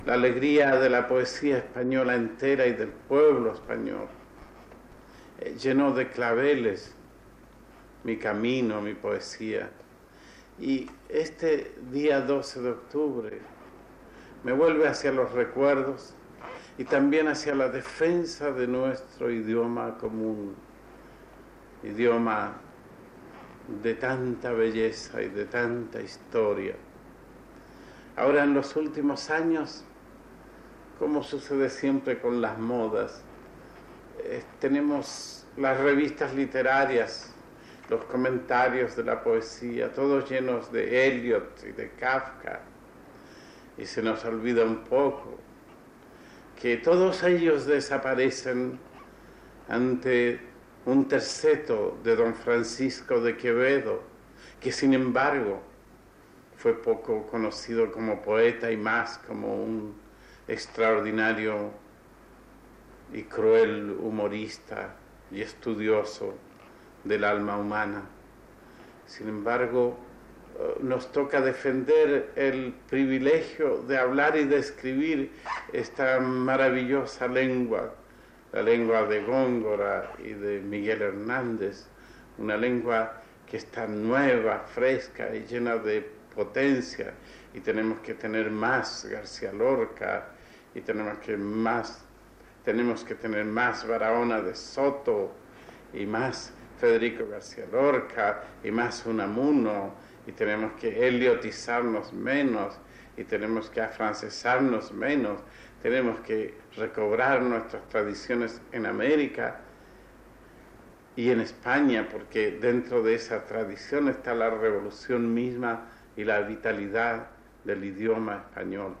Paraules de l'escriptor Pablo Neruda sobre la llengua castellana i els grans escriptors castellans
Extret del programa "El sonido de la historia", emès per Radio 5 Todo Noticias el 13 d'octubre de 2012